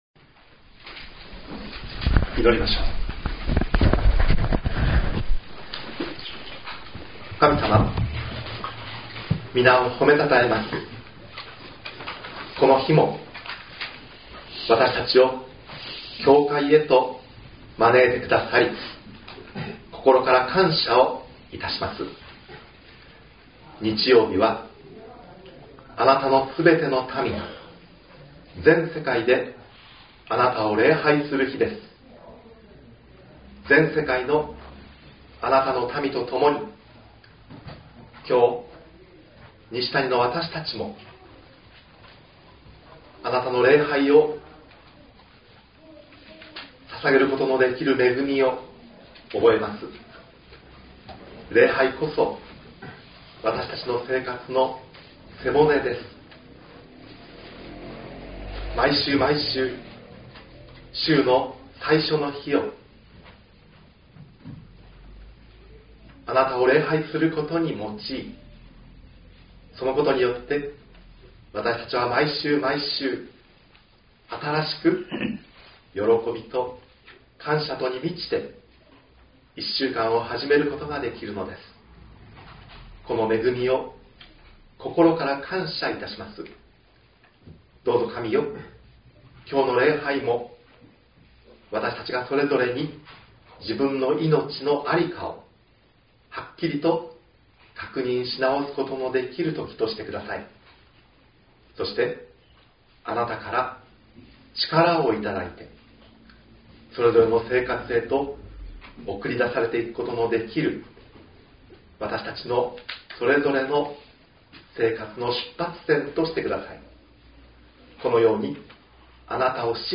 .mp3 ←クリックして説教をお聴きください。